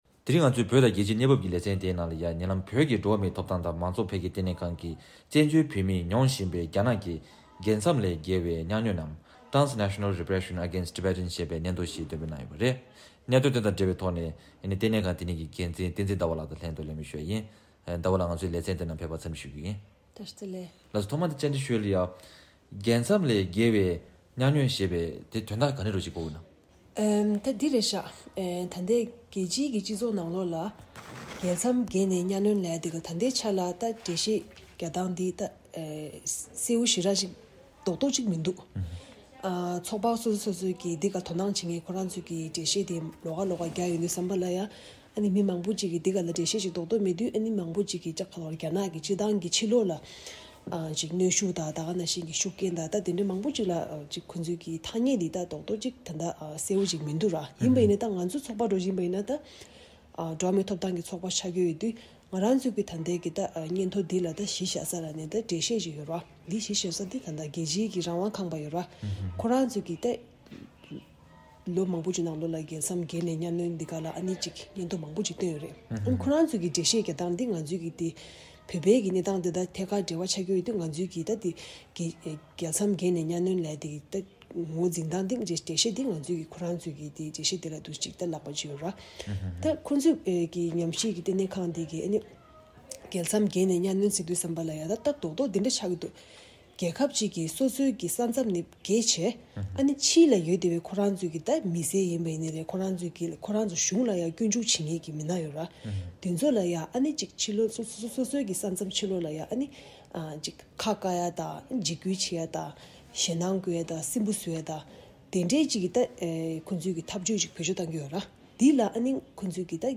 བཙན་བྱོལ་བོད་མིས་མྱོང་བཞིན་པའི་རྒྱ་ནག་གི་རྒྱལ་མཚམས་ལས་བརྒལ་བའི་གཉའ་གནོན་སྐོར་གྱི་སྙན་ཐོ་སྐོར་གླེང་མོལ་ཞུས་པ།